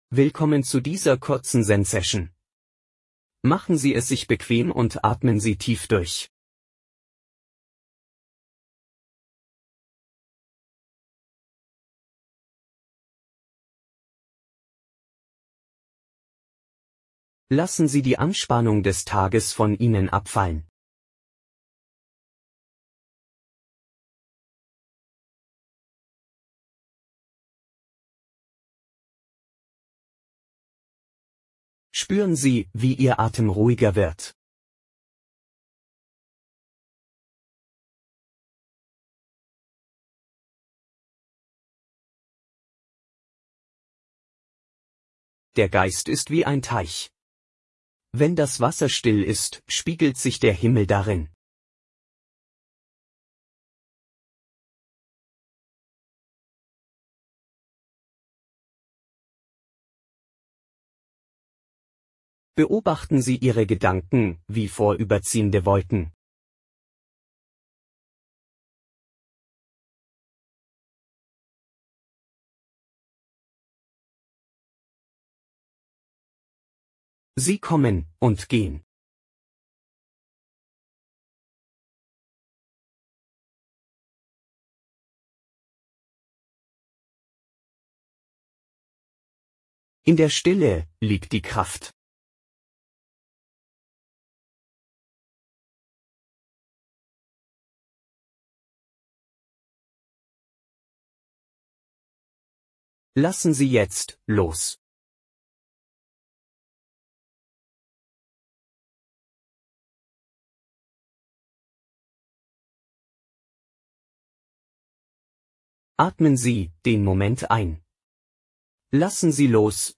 4-Minuten Zen-Session mit Gustav dem Wellensittich
Ein harmonisches Zusammenspiel von Vogelgezwitscher und Zen-Weisheiten, untermalt von Gustavs berühmtem "Omm".